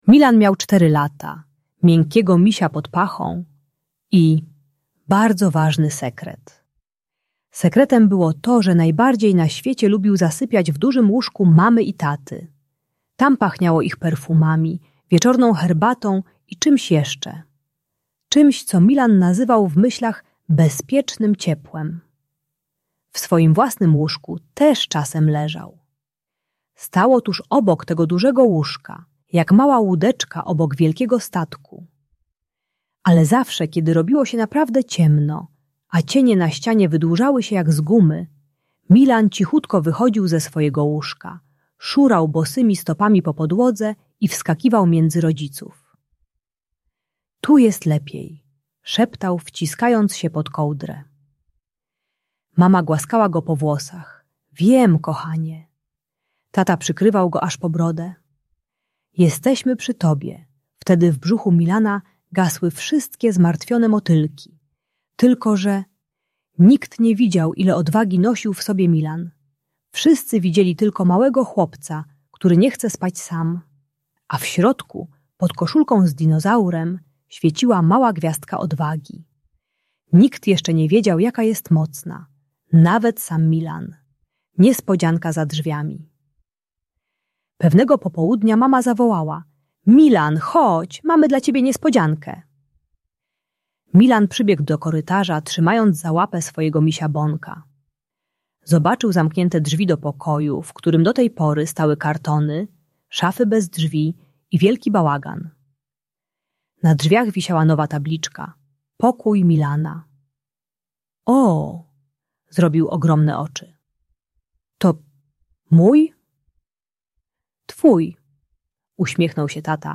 Pociąg do Krainy Snów – terapeutyczna bajka o odwadze i samodzielnym zasypianiu dla dzieci - Usypianie | Audiobajka